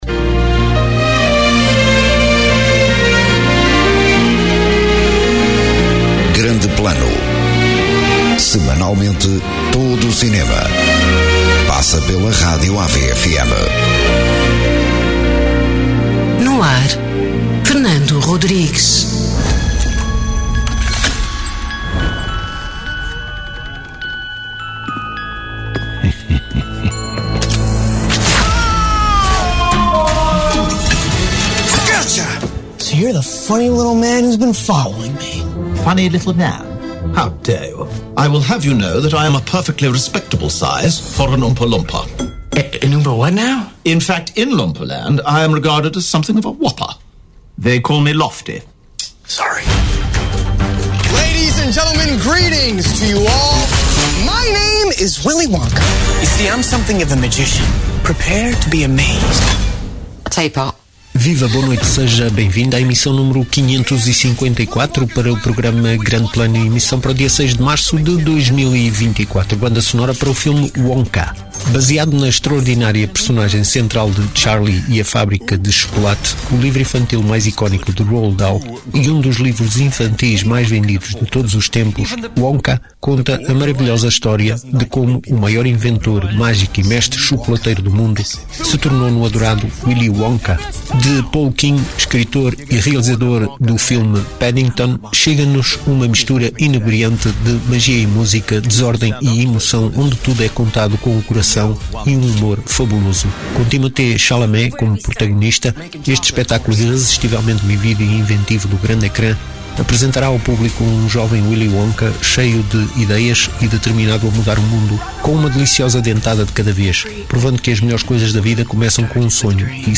Emissão: 554, 06 de Março 2024 Descrição: Cada programa divulga as estreias da semana a nível nacional e é complementado com a apresentação da banda sonora de um filme.